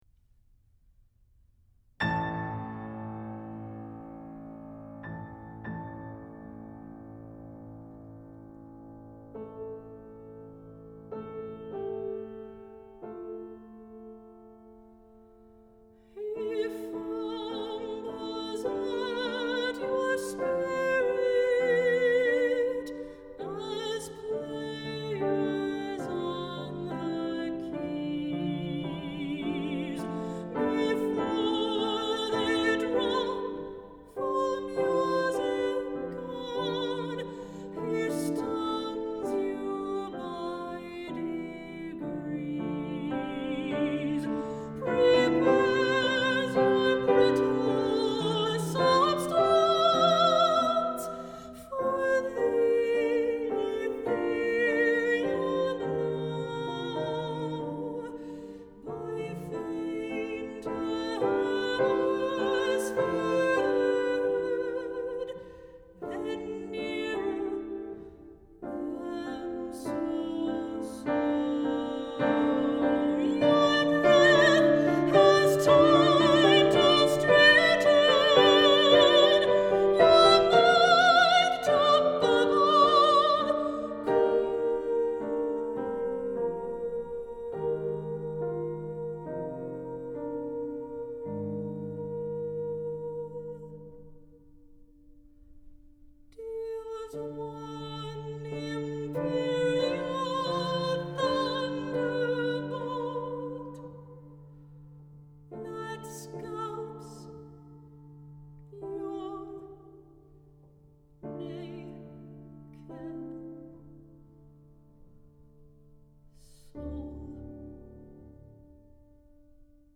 Medium, piano (c. 6:30)